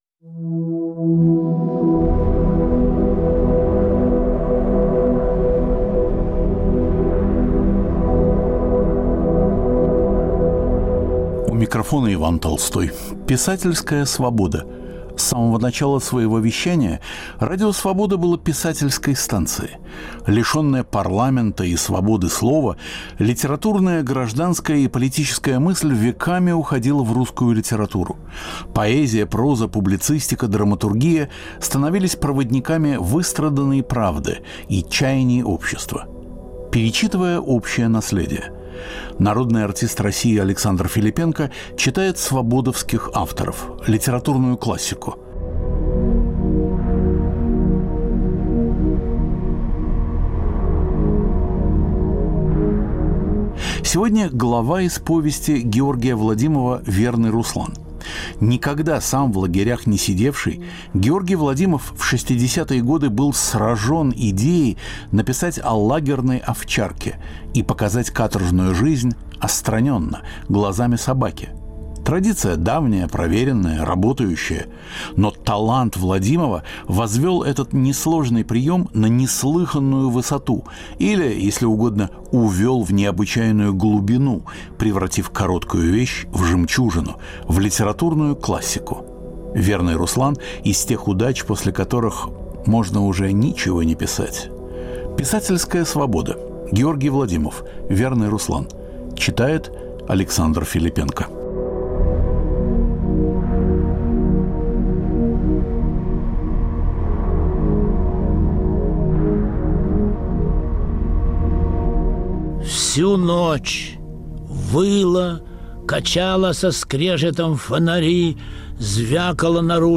Писательская Свобода. В цикле художественных чтений - глава из повести Георгия Владимова "Верный Руслан". Читает Александр Филиппенко.